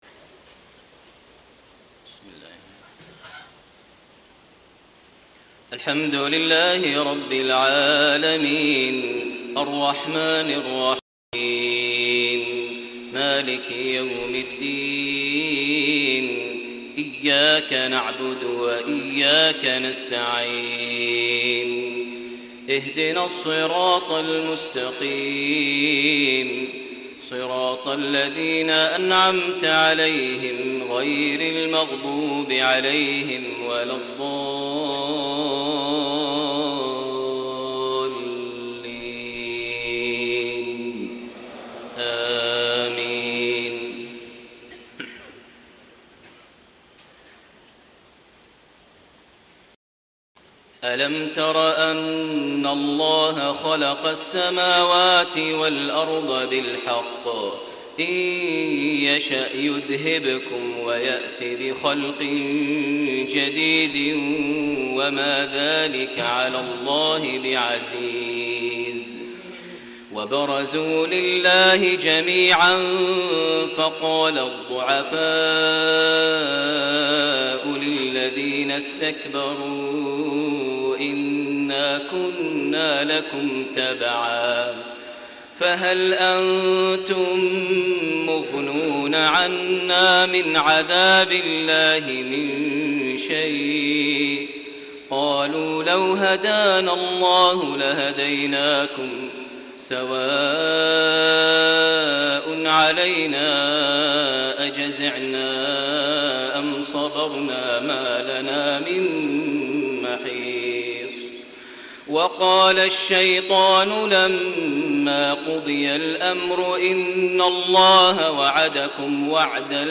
عشاء الأربعاء 3-1-1435هـ تلاوة مؤثرة خاشعة من سورة إبراهيم 19-27 > 1435 هـ > الفروض - تلاوات ماهر المعيقلي